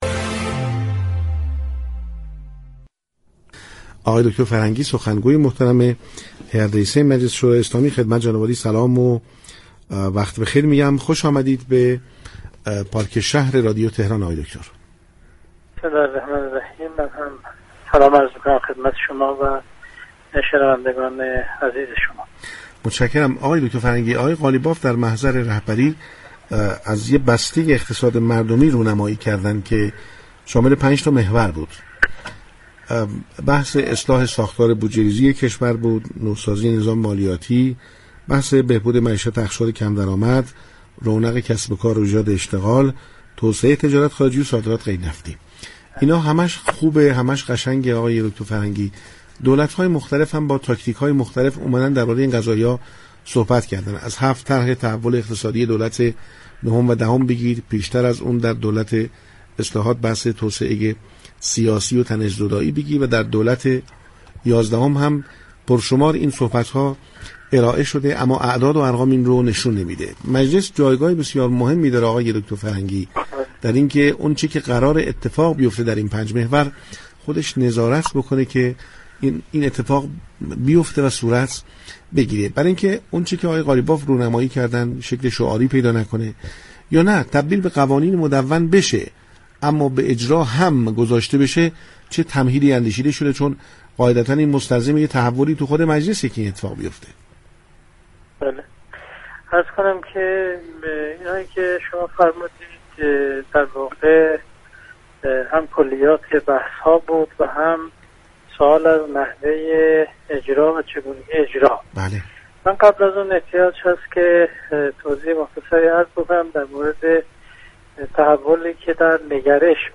محمدحسین فرهنگی در گفتگو با كارشناس خبری پاركشهر 23 تیرماه اظهار داشت: دیروز طرحی در مجلس مطرح شد و امروز در كمیسیون اقتصادی نهایی می‌شود چون دو فوریت آن رأی آورده است.